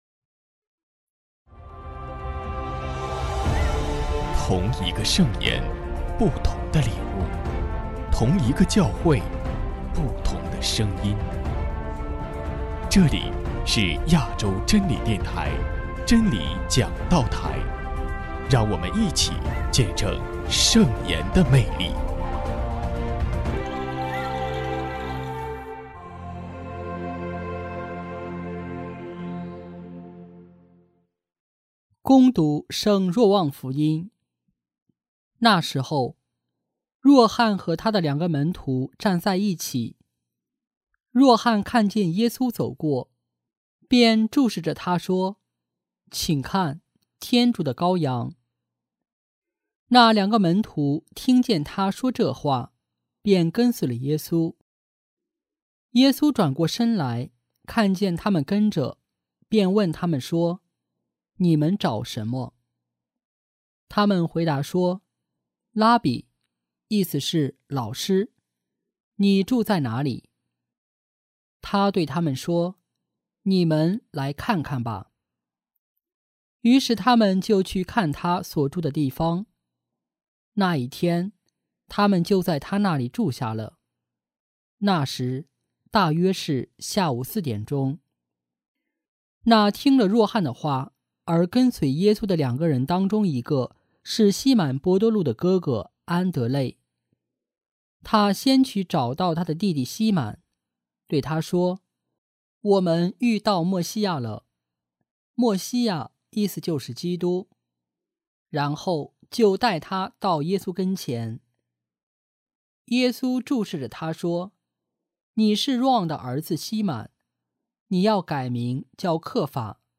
——基督的福音 证道 主题：身体聆听圣神，寻找圣神，服从圣神 弟兄姐妹们，主内平安。